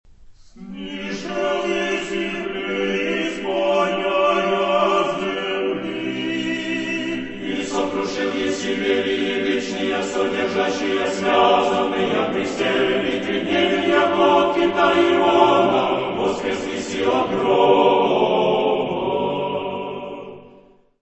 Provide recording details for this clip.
Descrição Física: 1 Disco (CD) (55 min.) : stereo; 12 cm